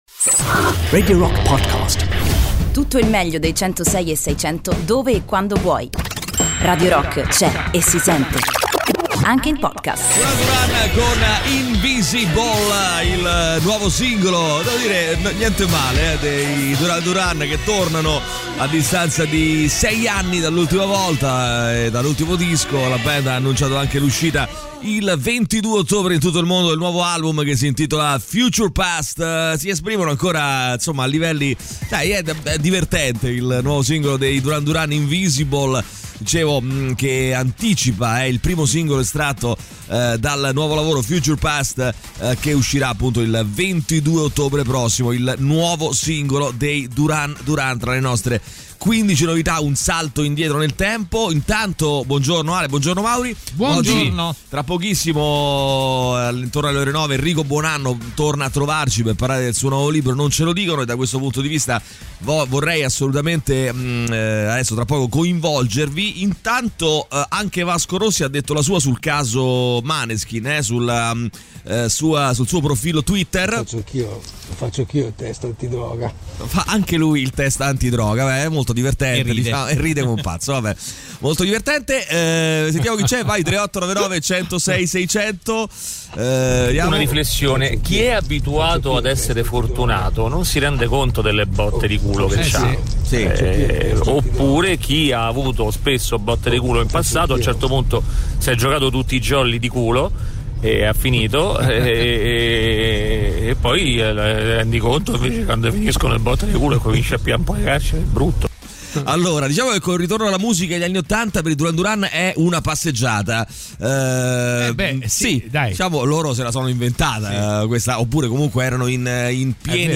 in diretta dalle 08.00 alle 10.00 dal Lunedì al Venerdì sui 106.6 di Radio Rock.